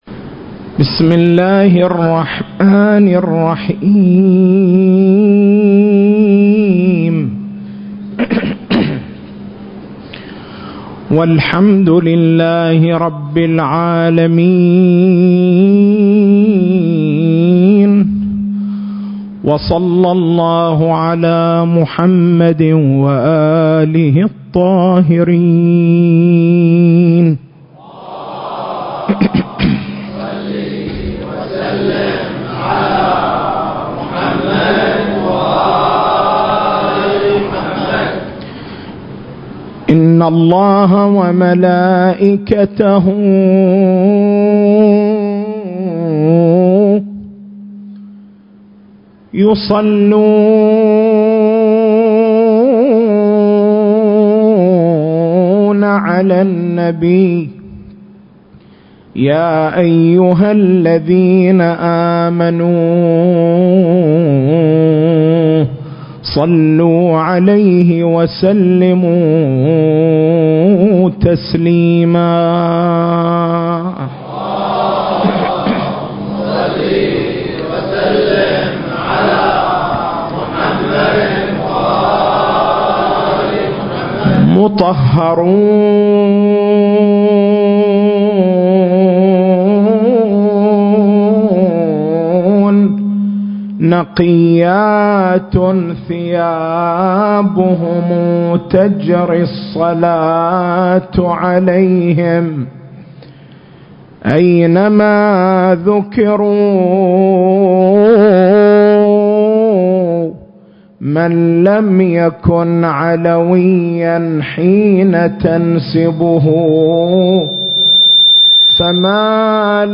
سلسلة: ولادة الإمام المهدي (عجّل الله فرجه) فوق التشكيك (6) نقاط البحث: - شهادة الأسرة - شهادة المؤرّخين - شهادة علماء الأنساب - شهادة علماء الملل والنحل المكان: حسينية الحاج حبيب العمران التاريخ: 1440 للهجرة